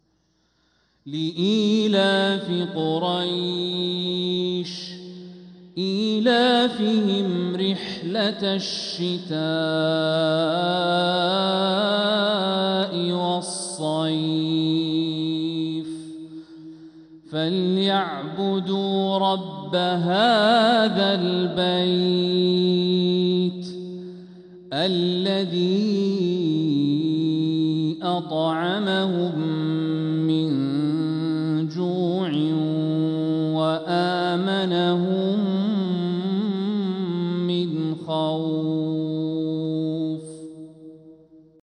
من الحرم المكي 🕋